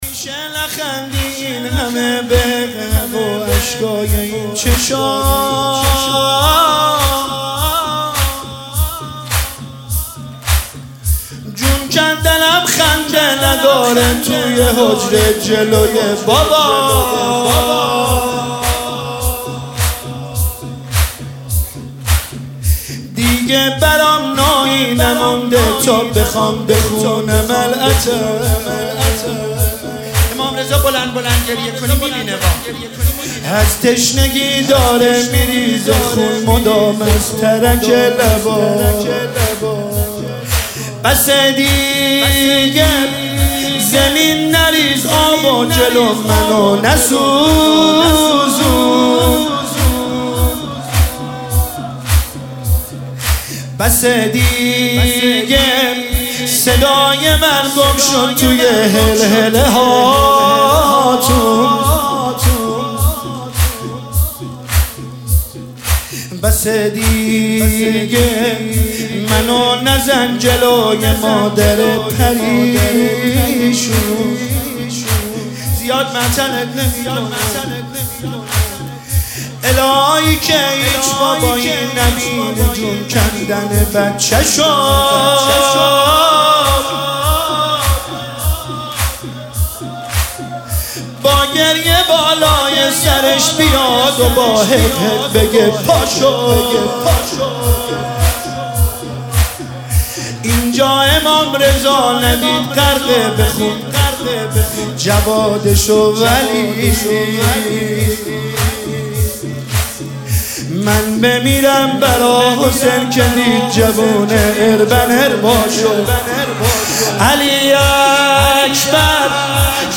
مداحی زمینه